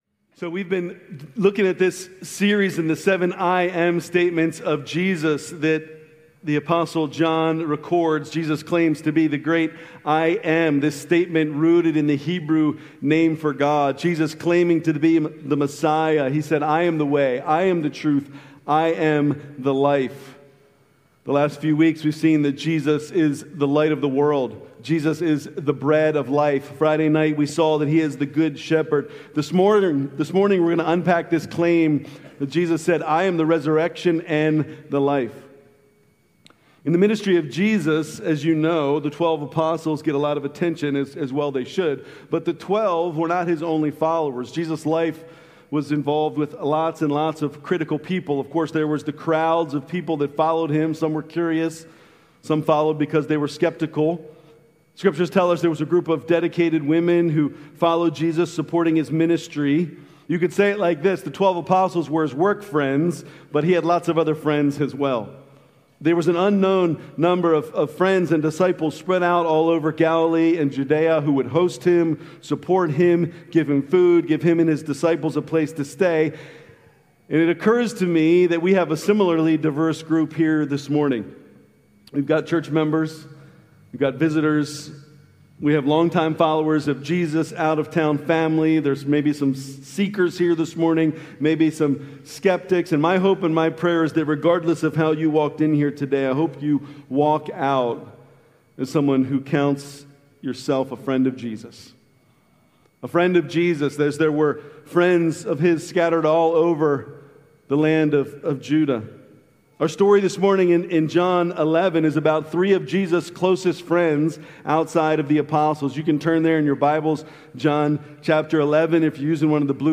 April 4, 2026 – Easter Sunday Worship Service